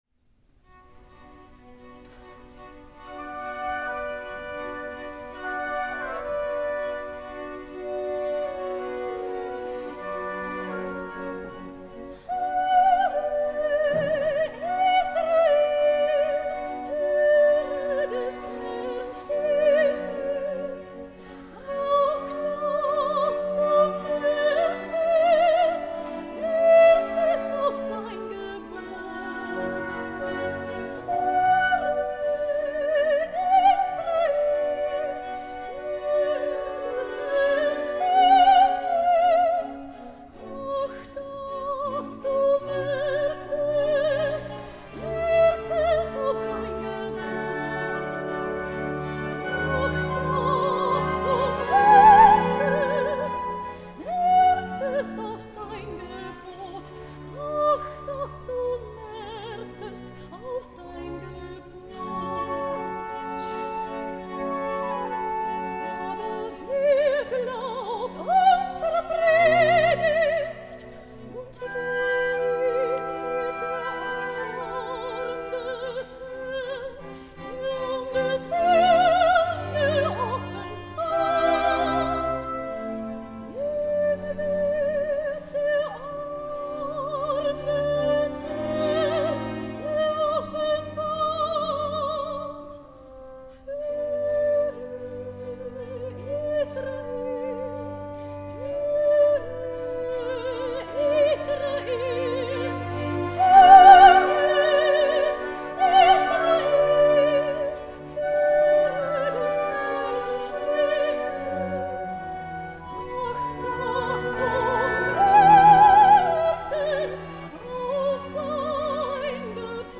Sängerin | Sopran